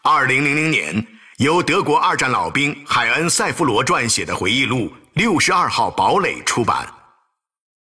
Autorytatywny Lektor AI Wprowadzający do Szkoły
Tekst na Mowę
Ton Akademicki
Czysta Artykulacja